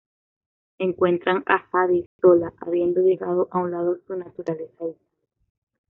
dis‧tan‧te
/disˈtante/